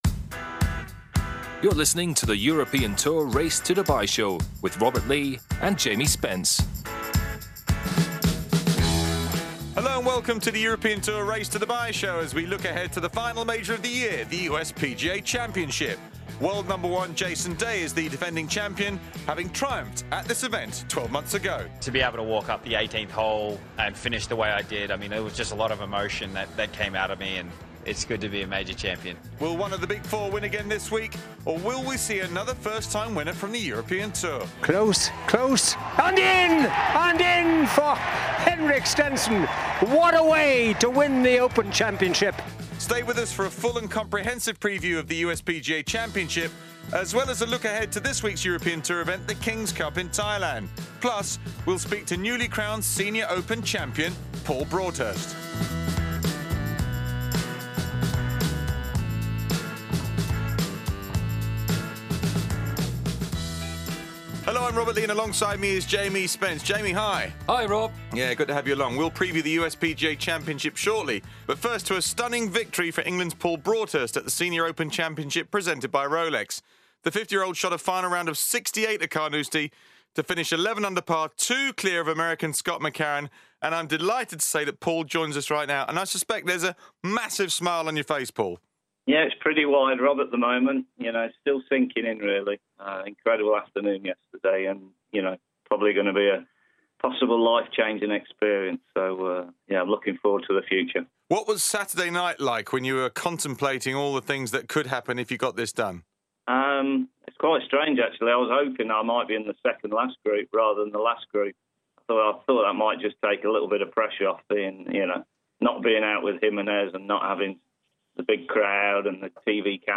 There’s also a look ahead to the King’s Cup in Thailand and an exclusive interview with Paul Broadhurst, winner of the Senior Open Championship Presented by Rolex.